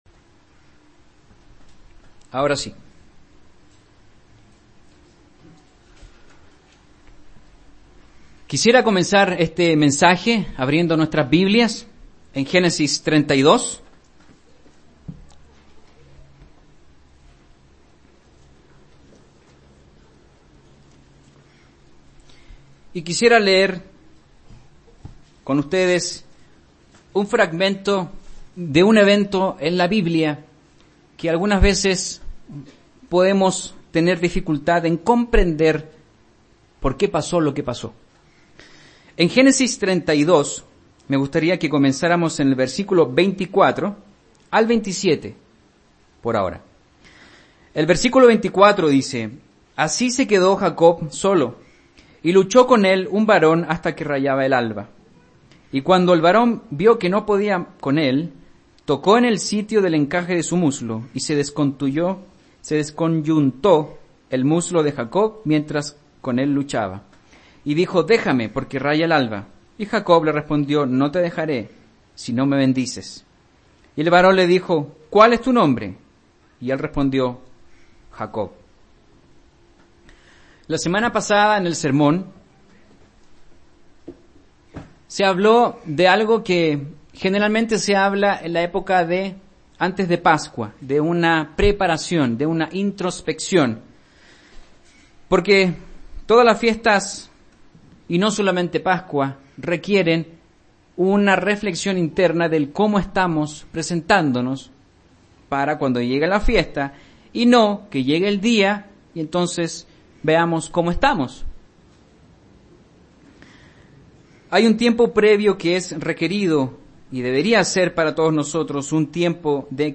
Transcripción